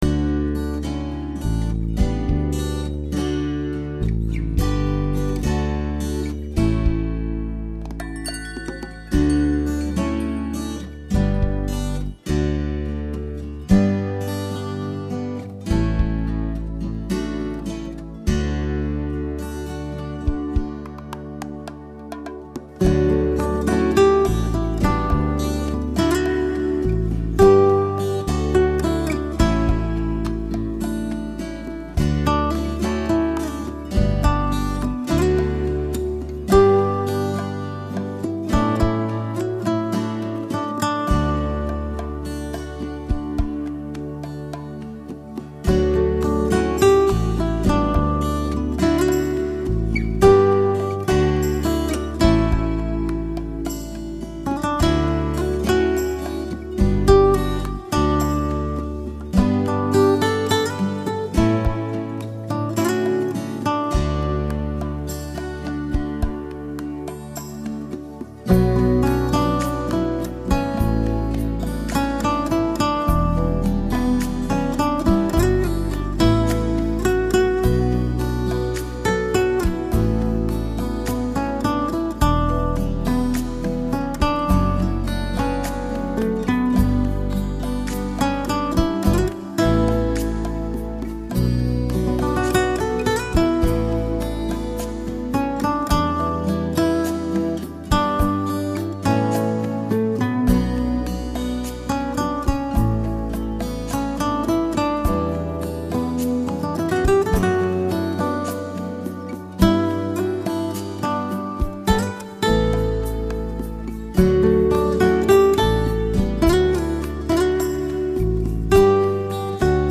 在无忧无虑的热带敲击节奏的烘托下，轻快流畅的旋律从指尖流淌出来。
但是吉他声音也录得通透、清澈、传真和富有空气感，把乐意表现得细致入微、丝丝入扣。